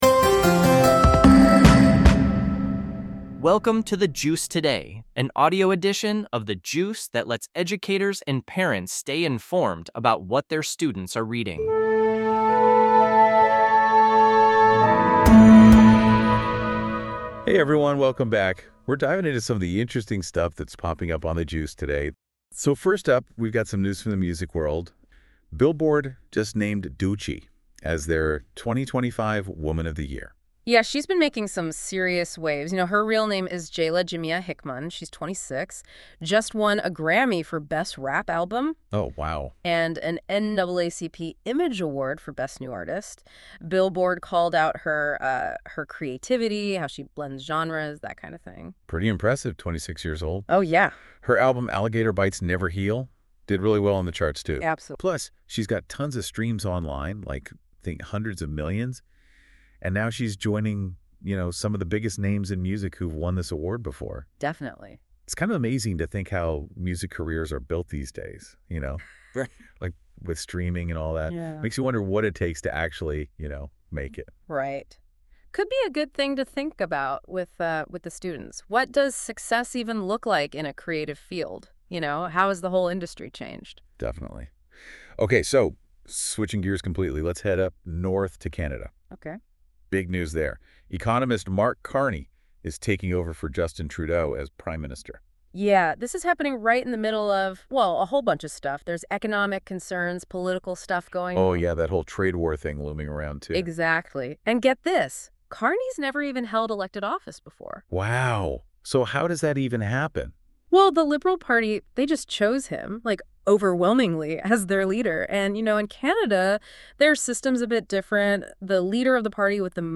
This podcast is produced by AI based on the content of a specific episode of The Juice.